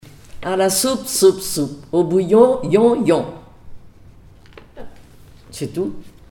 formulette enfantine : amusette
comptines et formulettes enfantines
Pièce musicale inédite